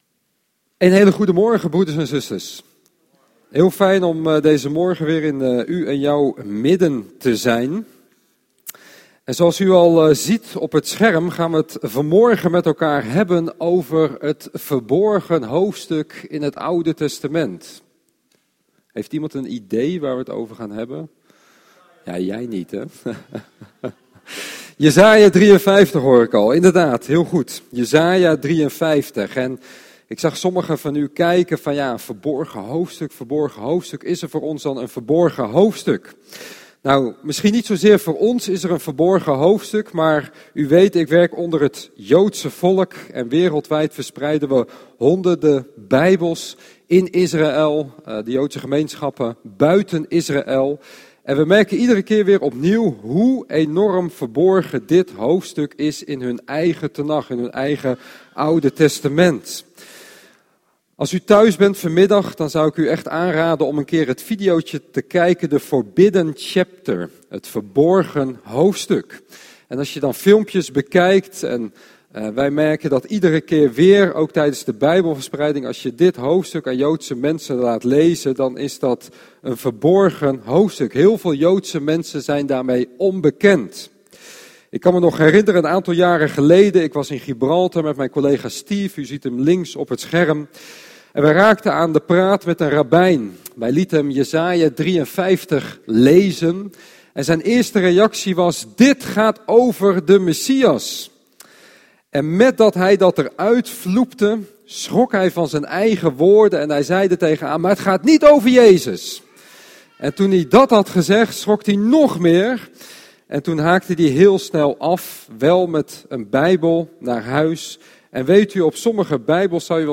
Luister hier gratis 200+ audio-opnames van preken tijdens onze evangelische diensten en blijf verbonden met Jezus!